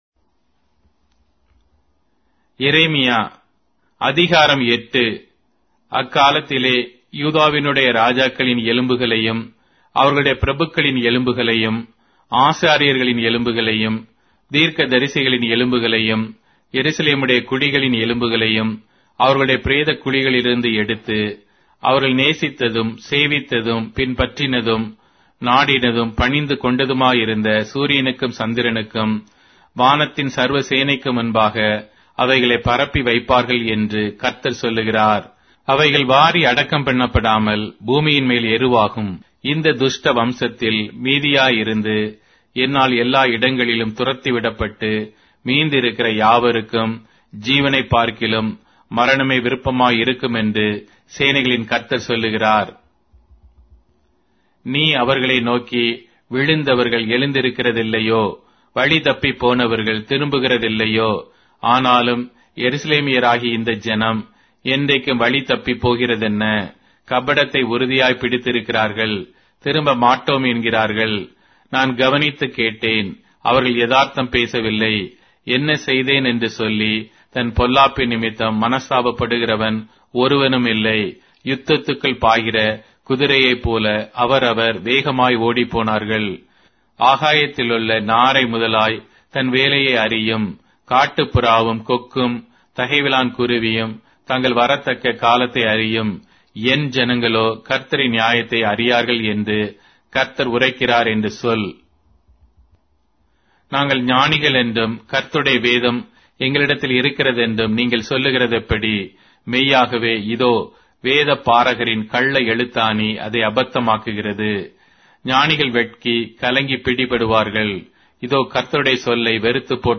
Tamil Audio Bible - Jeremiah 47 in Erven bible version